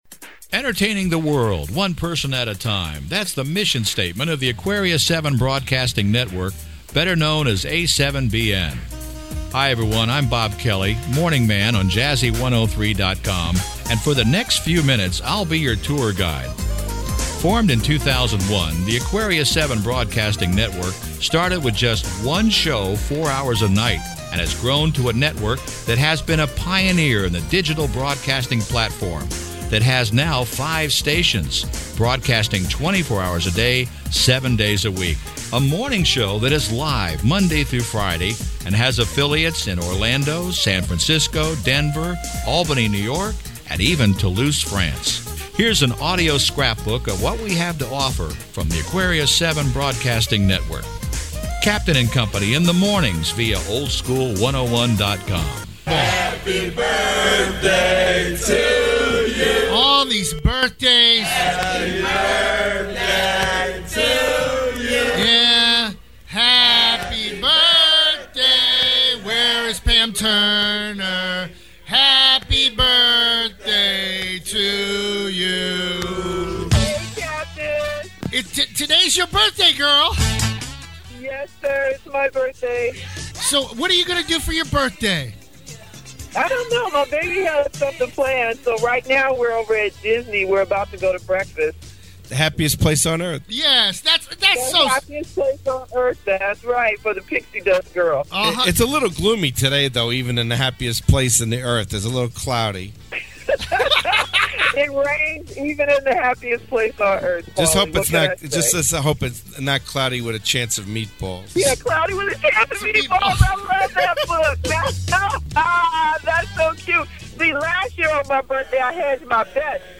R&B R&B More Info Close